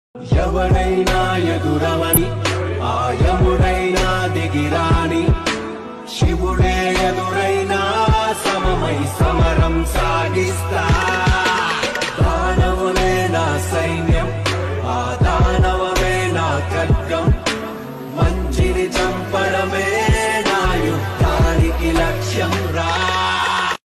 best flute ringtone download | mass song ringtone